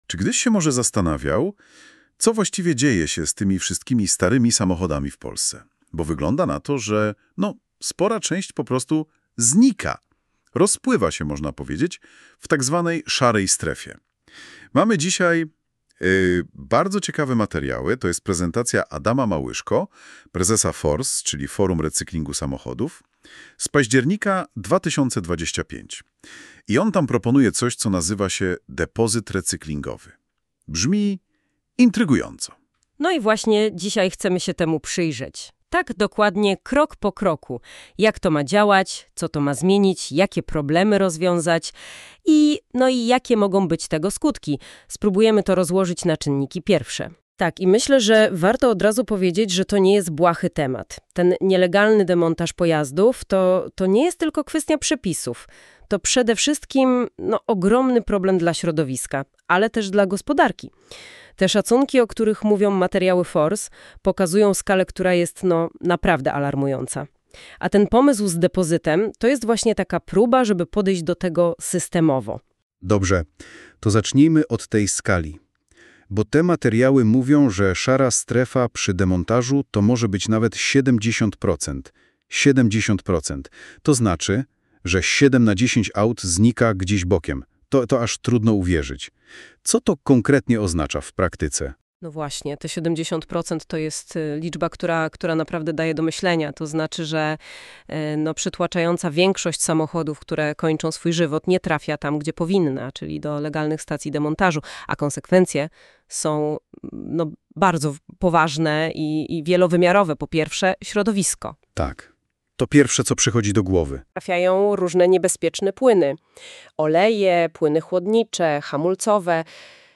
przygotowanie za pomocą sztucznej inteligencji podcastu o naszej propozycji systemu Depozytu Recyklingowego